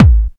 Kick
Original creative-commons licensed sounds for DJ's and music producers, recorded with high quality studio microphones.
Loud Kick Sound A Key 314.wav
good-kickdrum-a-key-319-JI1.wav